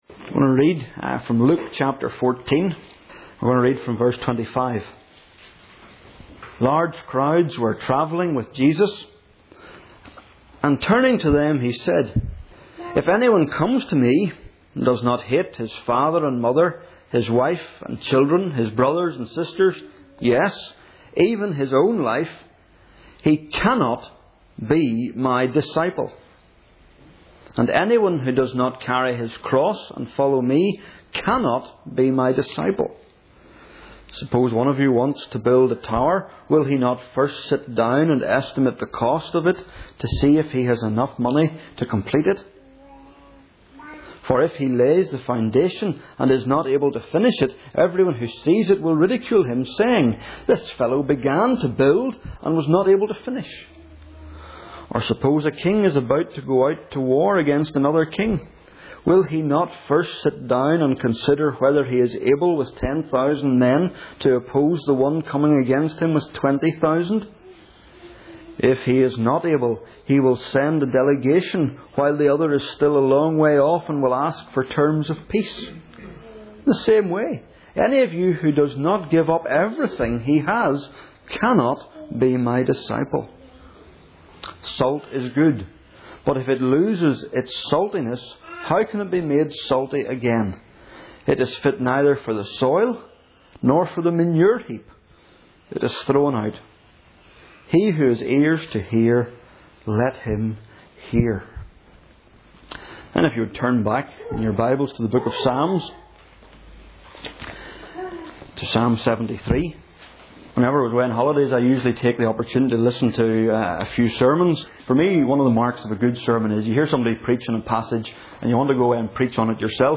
Psalms | Single Sermons | new life fellowship